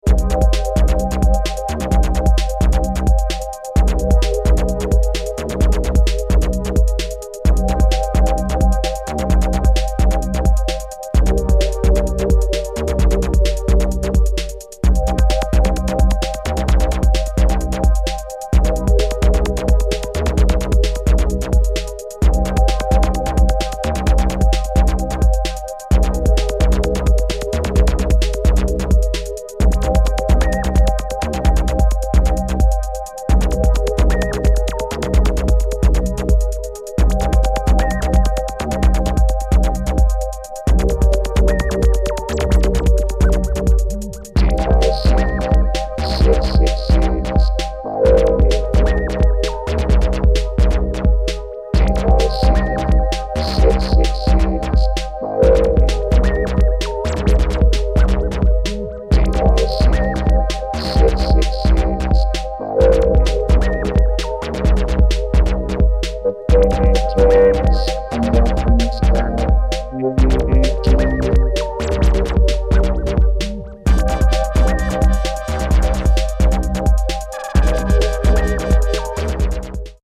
Mysterious 5 track death electro & fear acid compilation.